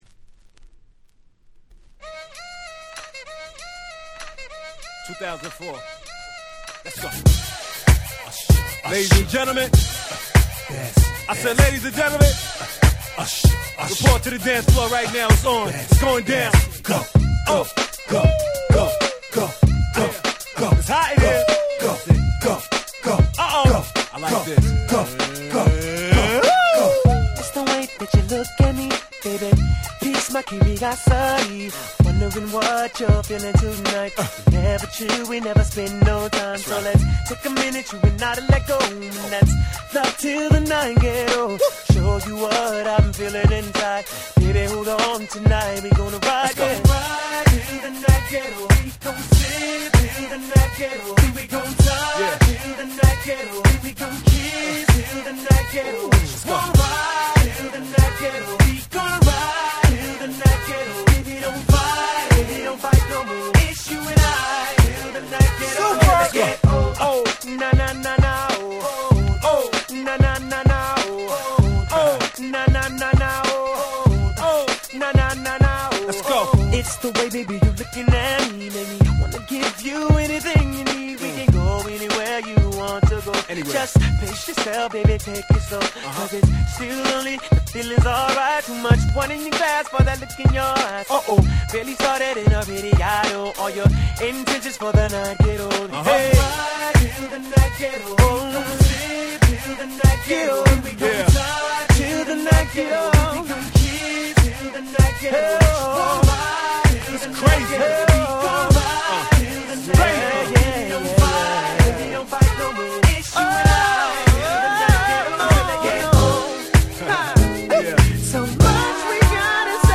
04' Nice R&B !!